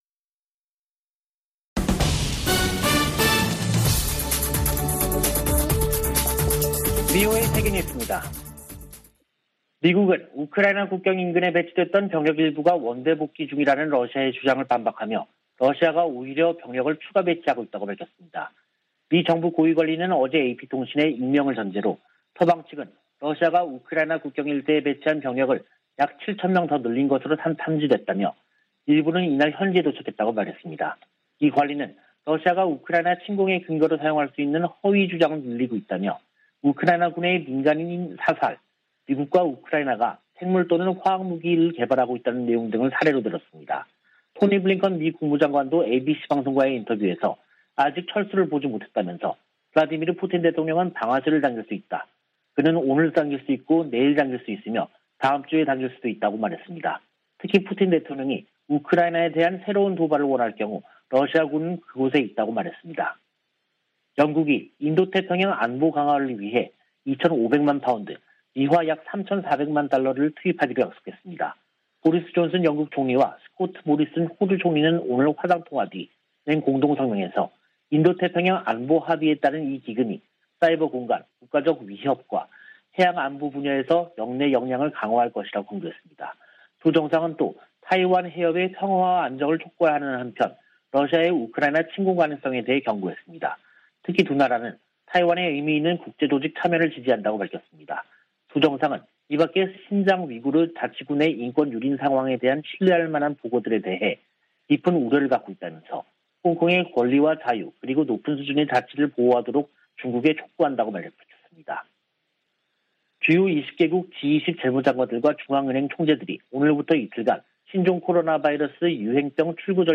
VOA 한국어 간판 뉴스 프로그램 '뉴스 투데이', 2022년 2월 17일 3부 방송입니다. 미국이 핵탄두 탑재 가능한 B-52H 전략폭격기 4대를 괌에 배치했습니다. 미국의 전문가들은 필요하다면 한국이 우크라이나 사태 관련 미국 주도 국제 대응에 동참해야한다는 견해를 제시하고 있습니다. 북한이 한 달 새 가장 많은 미사일 도발을 벌였지만 미국인들의 관심은 낮은 것으로 나타났습니다.